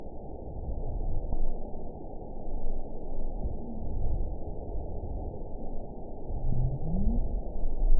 event 921701 date 12/16/24 time 22:48:31 GMT (4 months, 2 weeks ago) score 9.09 location TSS-AB03 detected by nrw target species NRW annotations +NRW Spectrogram: Frequency (kHz) vs. Time (s) audio not available .wav